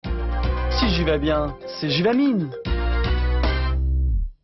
french old adverisment (loop)